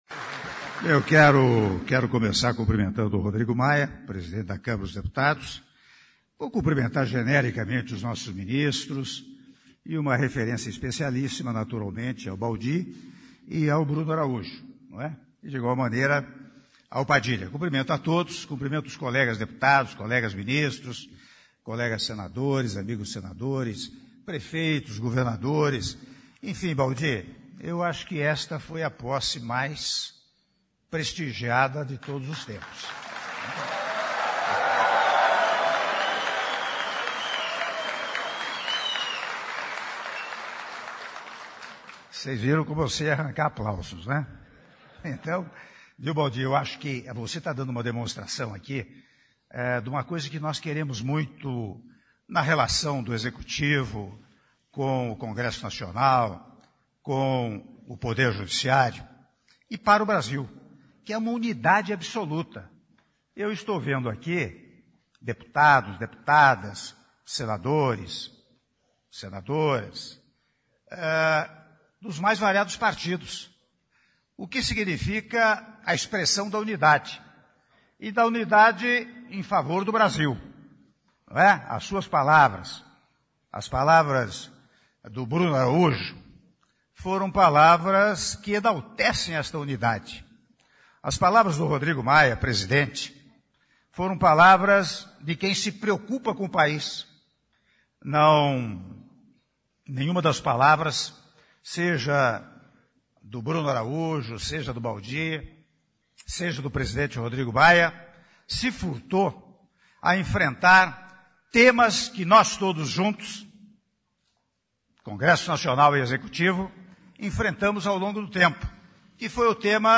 Áudio do discurso do Presidente da República, Michel Temer, durante cerimônia de posse do Ministro das Cidades, Alexandre Baldy -Brasília/DF (09min37s)